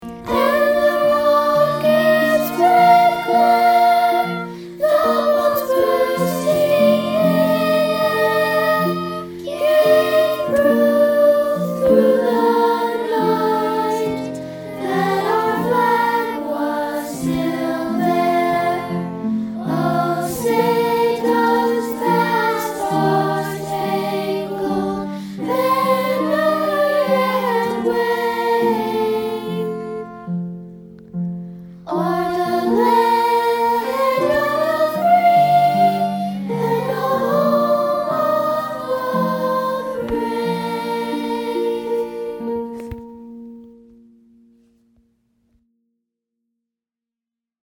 America's National Anthem